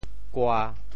柯 部首拼音 部首 木 总笔划 9 部外笔划 5 普通话 kē 潮州发音 潮州 gua1 姓 ko1 文 潮阳 gua1 姓 ko1 文 澄海 gua1 姓 ko1 文 揭阳 gua1 姓 ko1 文 饶平 gua1 姓 ko1 文 汕头 gua1 姓 ko1 文 中文解释 潮州 gua1 姓 对应普通话: kē 姓：～受良。